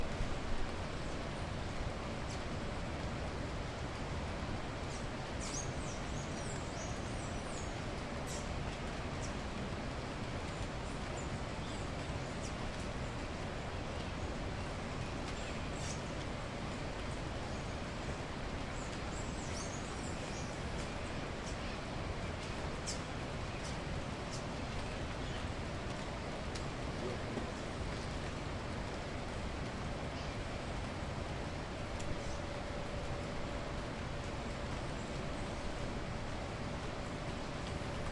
长笛独奏 " PANFLUTE SOLO 01 94 Em
标签： 民谣 下载 环路 安第斯山脉 样品 免费 panflute 赞菲尔 拉丁 独奏
声道立体声